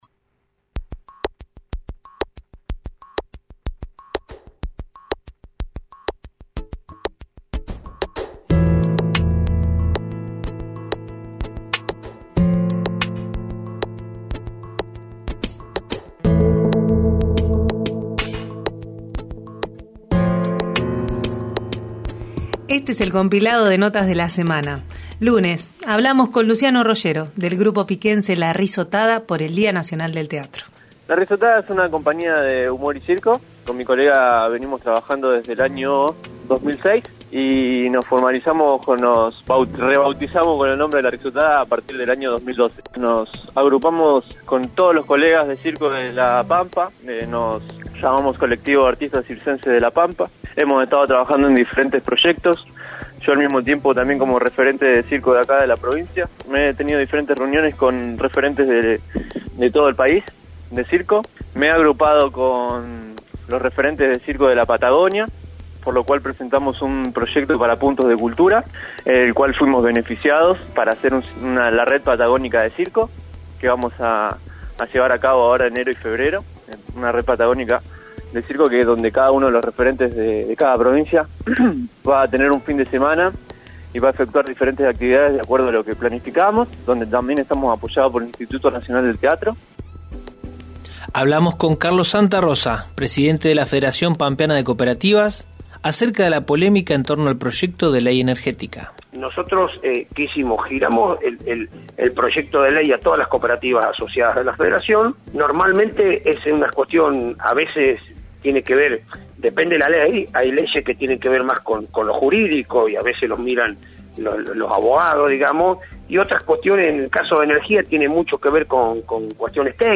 Compartimos algunos de los títulos destacados de la semana. Un resumen de las voces que pasaron por Radio Nacional Santa Rosa.